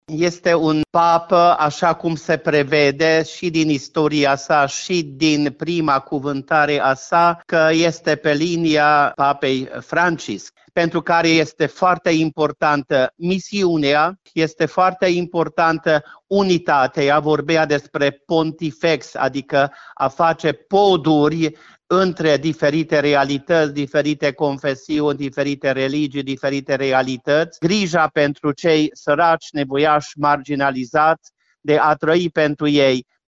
Alegerea Papei Leon al XIV-lea este una dintre cele mai rapide din istorie, spune Episcopul romano-catolic de Timișoara, Iosif Csaba Pál.
Iosif-Csaba-Pal-Papa-Leon-1.mp3